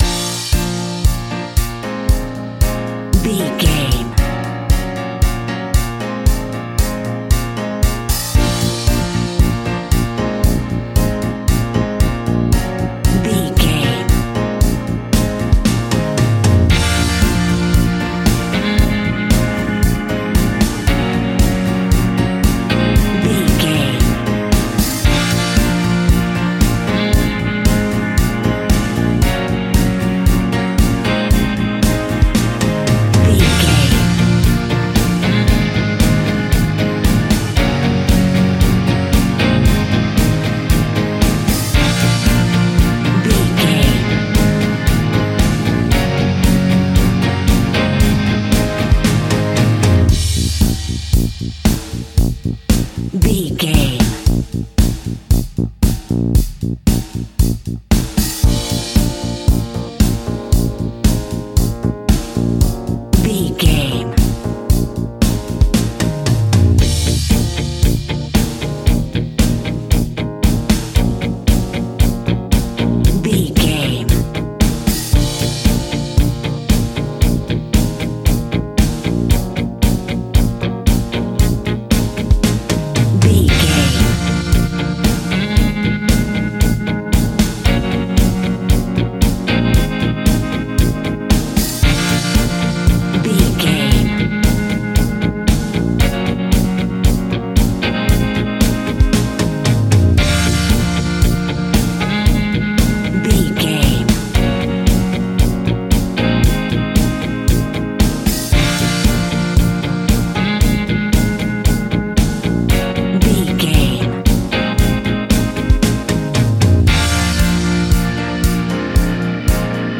Fast paced
Mixolydian
B♭
pop rock
indie pop
fun
energetic
uplifting
acoustic guitars
drums
bass guitar
electric guitar
piano
electric piano
organ